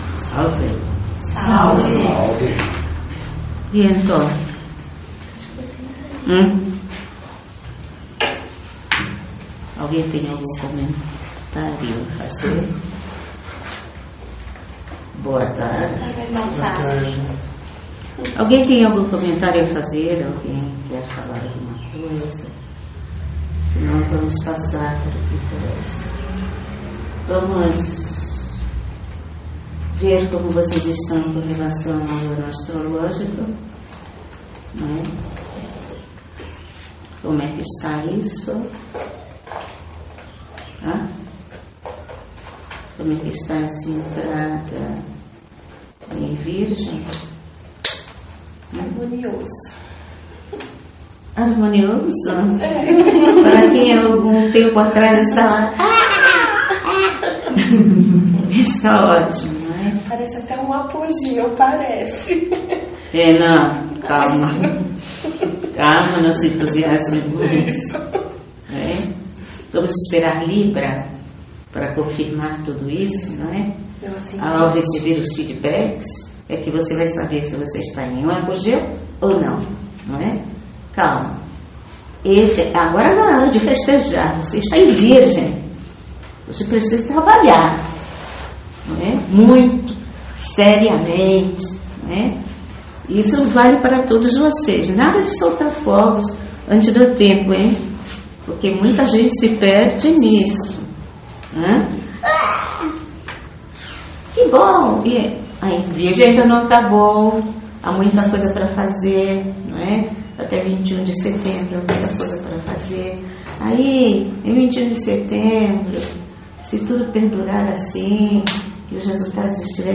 Perguntas e respostas